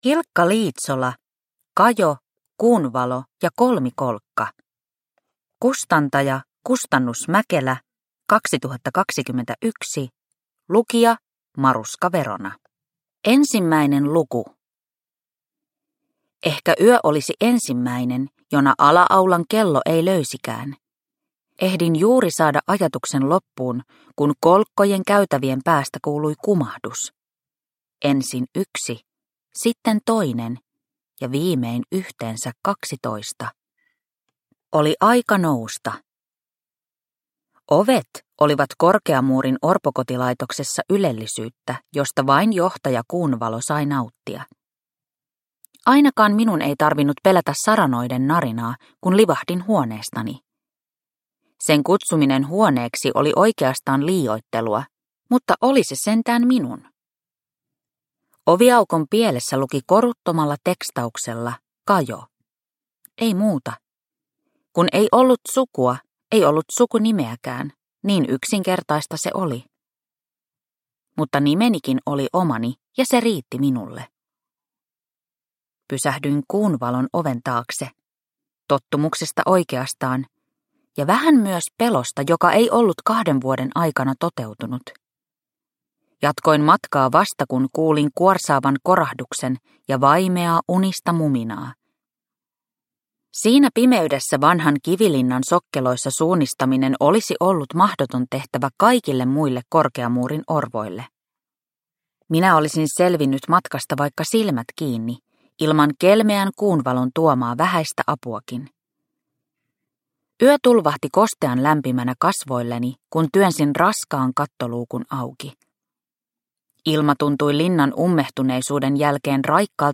Kajo, Kuunvalo ja Kolmikolkka – Ljudbok – Laddas ner